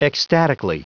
Prononciation du mot ecstatically en anglais (fichier audio)
Prononciation du mot : ecstatically
ecstatically.wav